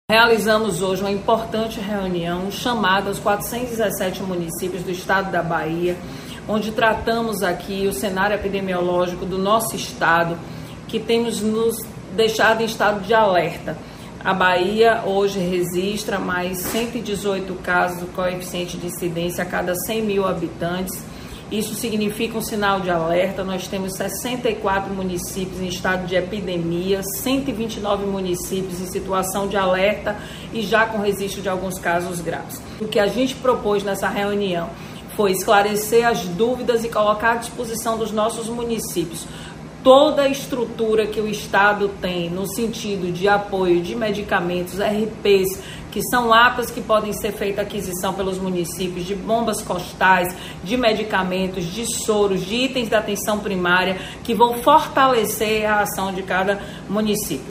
Secretária estadual da saúde, Roberta Santana, confirma a quarta morte por dengue na Bahia